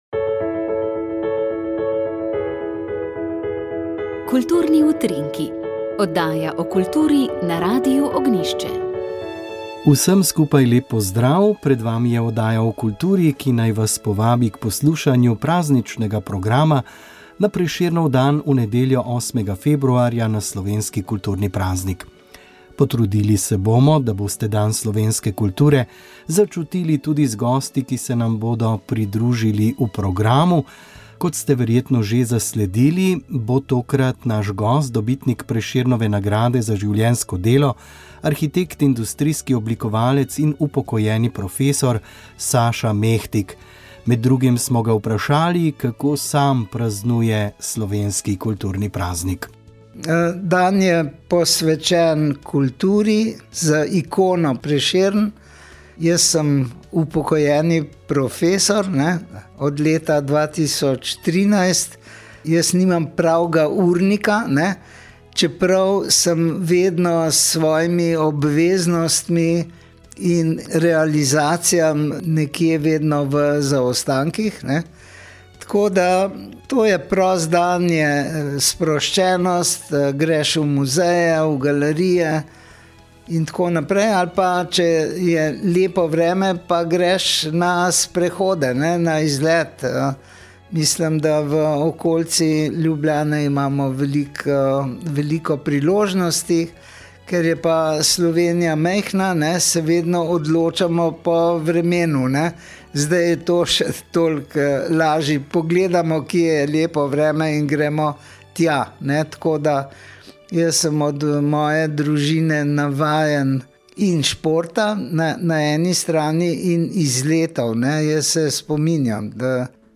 Molili so radijski sodelavci.